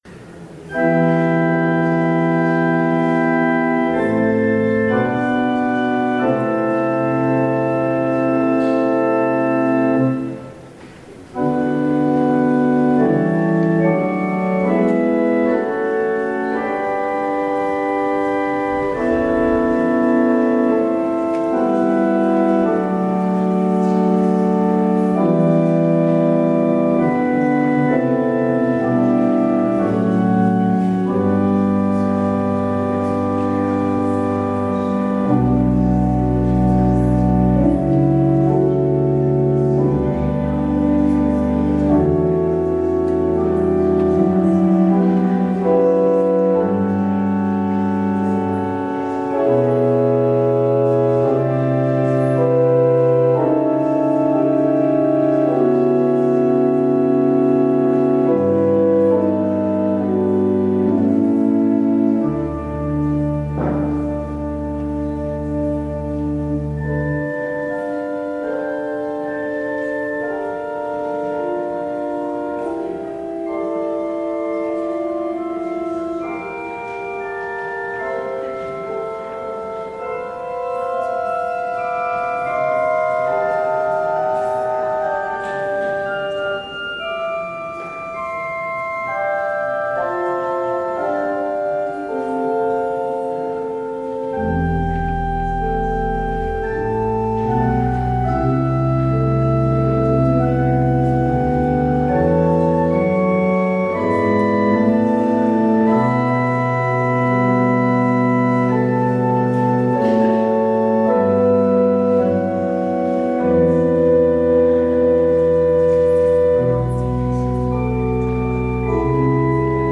Audio recording of the 10am hybrid/streamed service